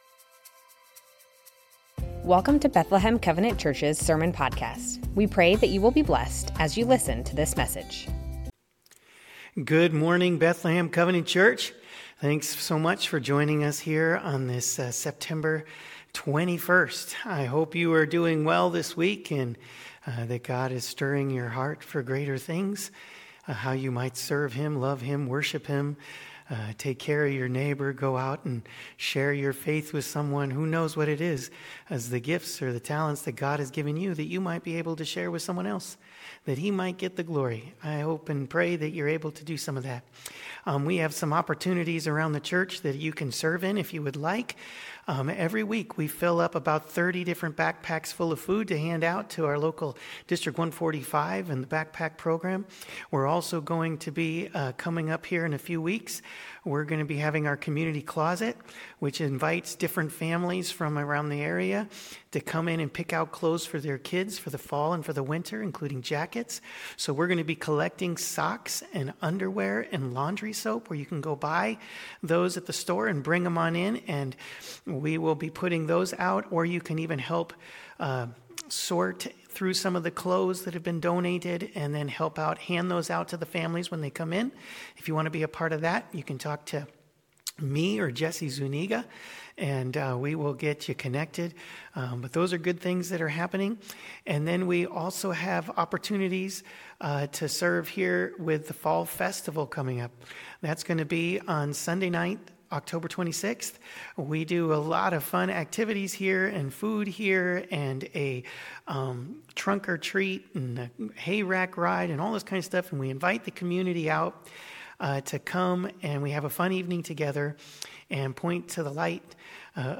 Bethlehem Covenant Church Sermons The Names of God - El Elyon Sep 21 2025 | 00:39:07 1x 00:00 / 00:39:07 Subscribe Share Spotify RSS Feed Share Link Embed